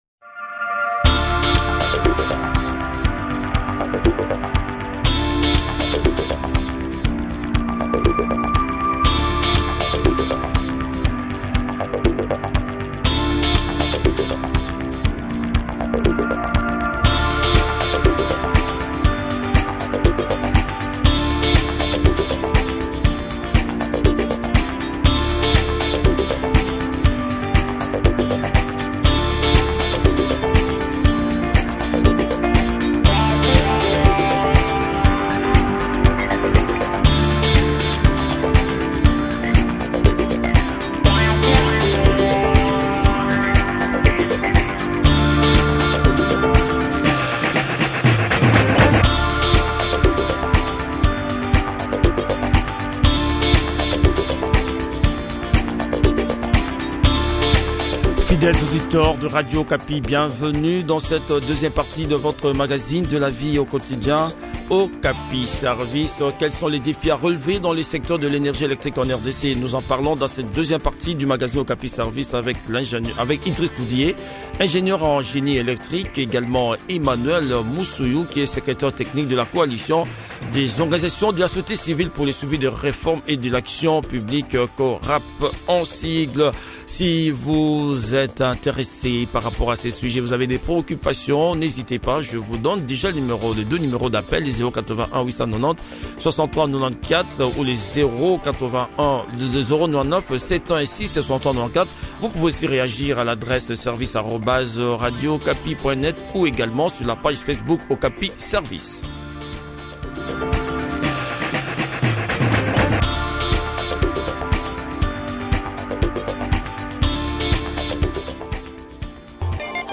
ingénieur en génie électrique.